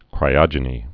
(krī-ŏjə-nē)